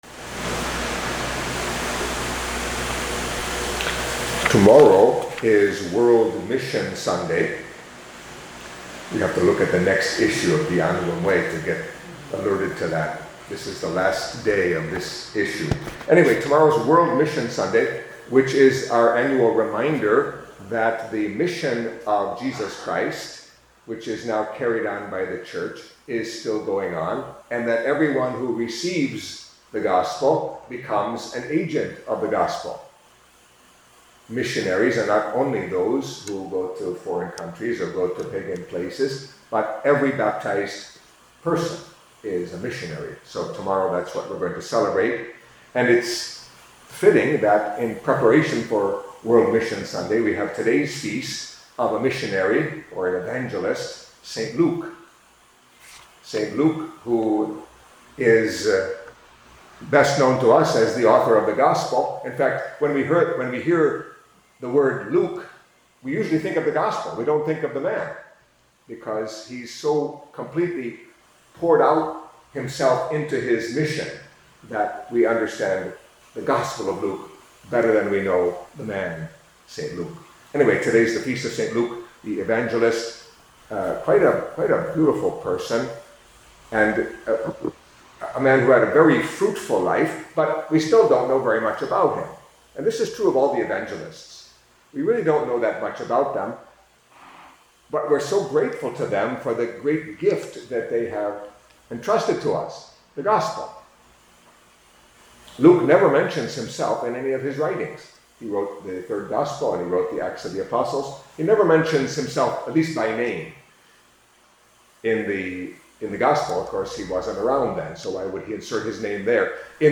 Catholic Mass homily for Feast of Saint Luke, Evangelist